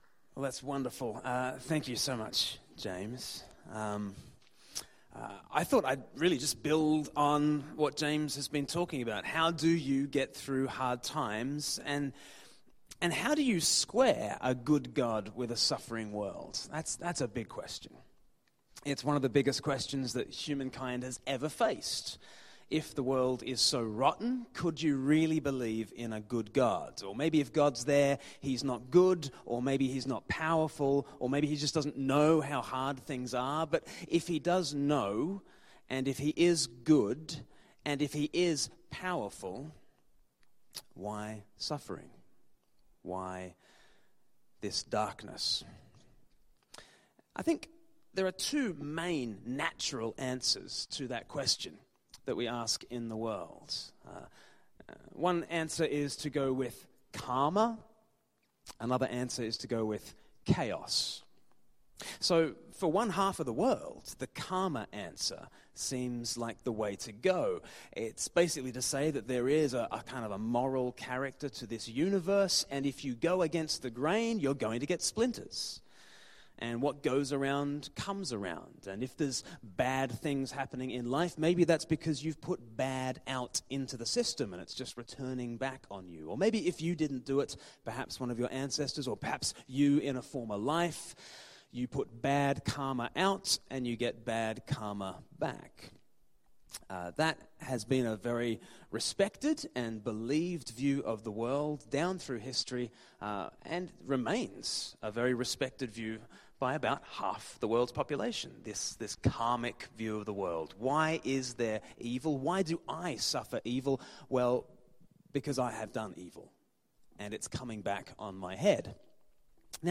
Seminar
Interview